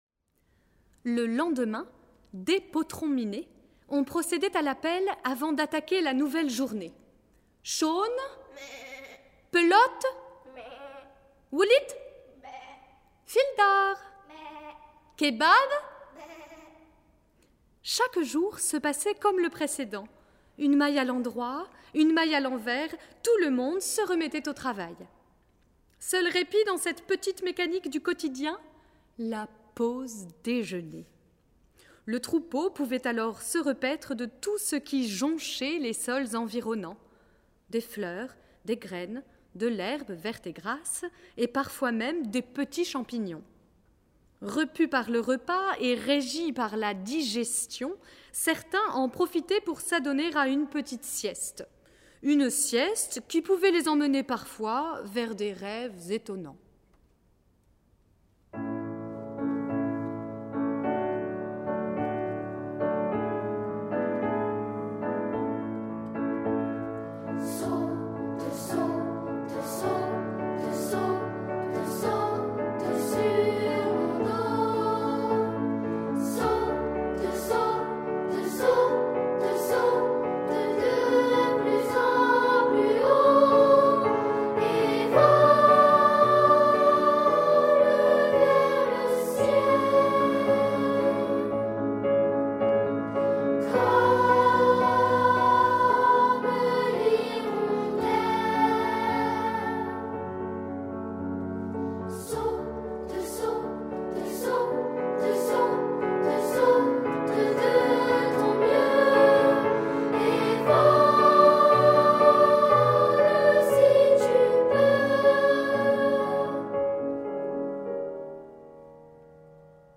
Genre :  ChansonComptine
Style :  Avec accompagnement
Enregistrement piano et voix